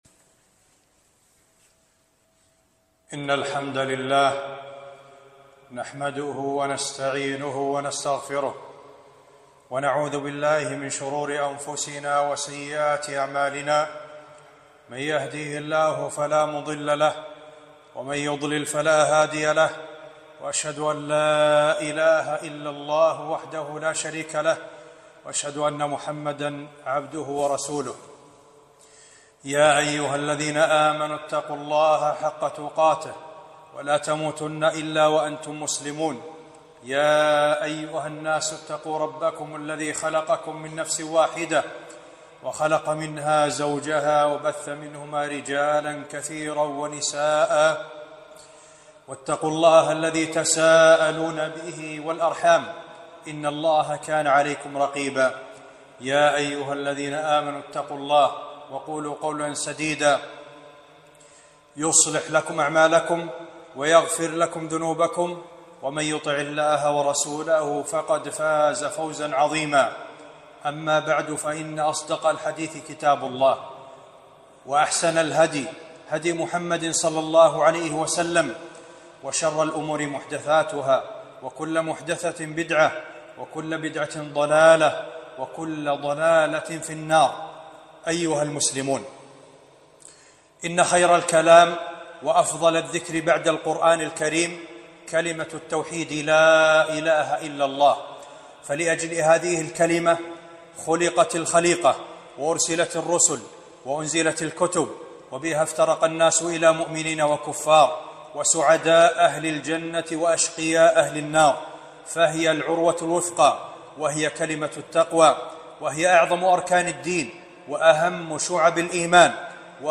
خطبة - فضل كلمة التوحيد لا إله إلا الله